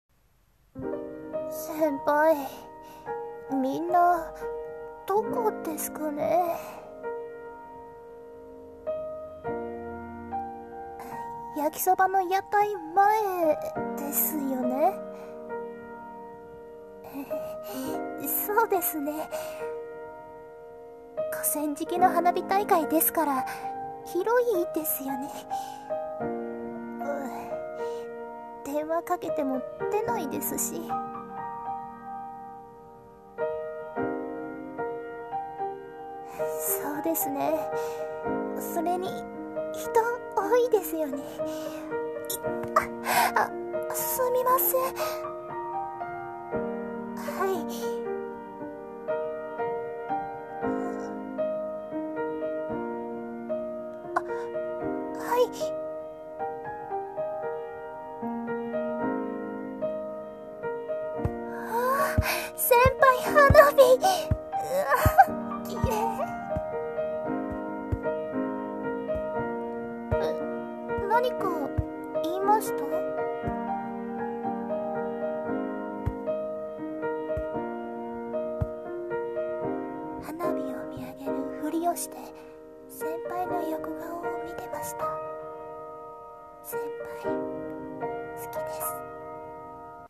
声劇【花火大会
朗読